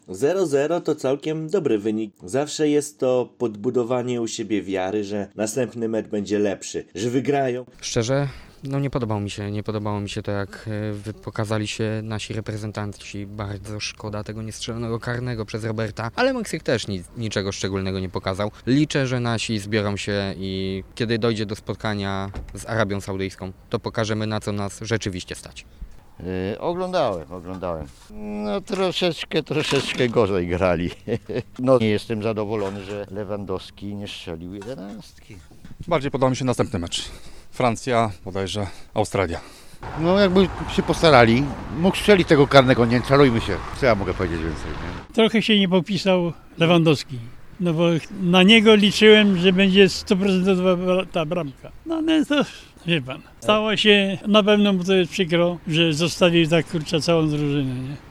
Zapytaliśmy mieszkańców Dolnego Śląska, co sądzą na temat wtorkowego spotkania i jakie są ich nastroje przed meczem z Arabią Saudyjską?